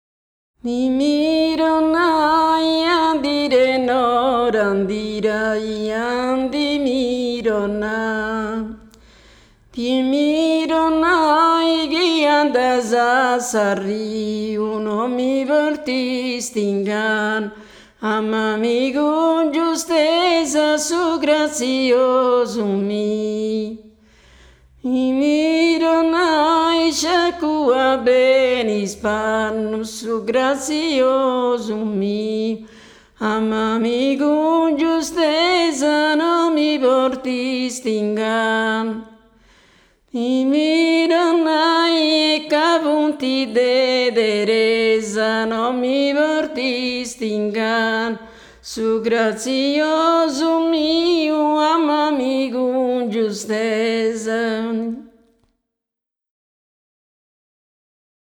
voice
L’interpretazione, che privilegia la ricchezza dell’ornamentazione alla potenza della voce, richiama lo stile a s’antiga. La chitarra, suonata ad arpeggio, segue i profili melodici proposti dalla voce e riempie gli spazi tra un verso e l’altro.
One version involves the repetition of a “nonsense” stock phrase that opens with the “iandimironnai” formula.
The execution foresees repetitions and intertwining between the two parts.